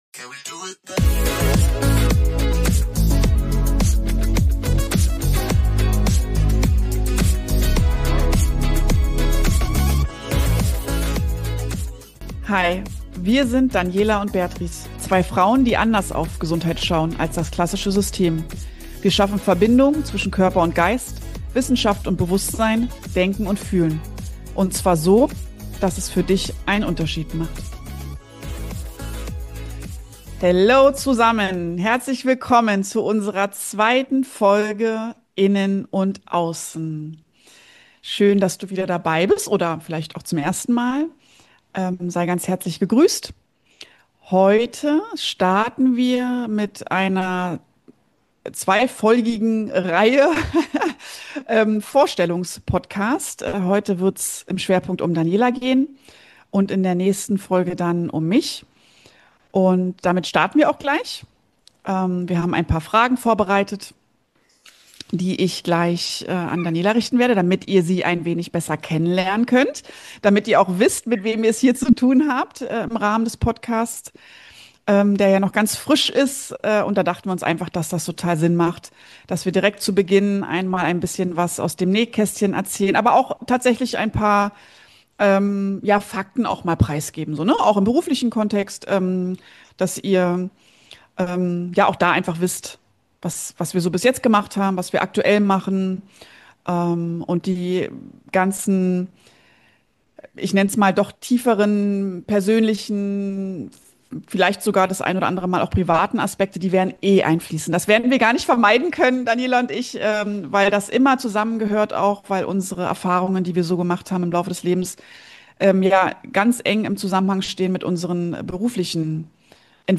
Ein Gespräch über Sinnfragen, stille Stärke und das Abenteuer Selbstständigkeit.